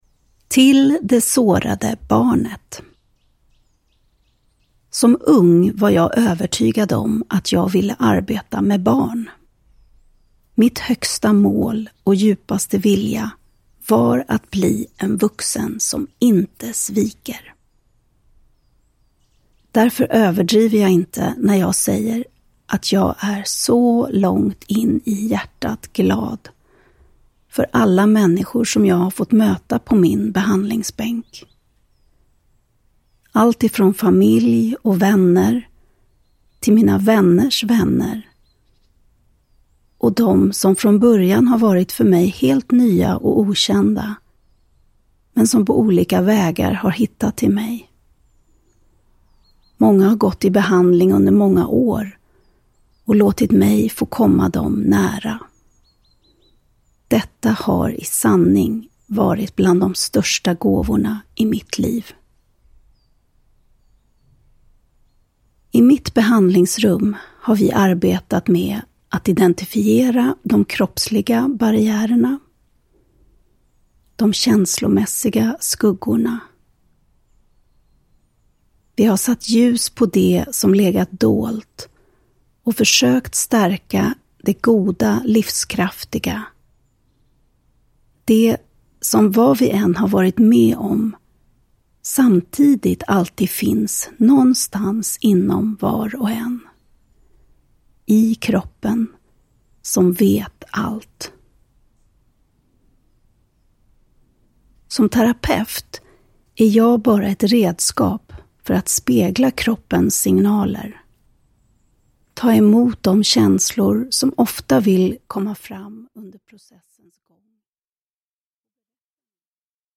Ljudbok
- en meditation till ditt inre barn